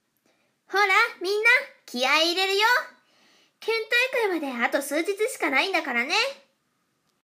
サンプルボイス 熱血 【少女】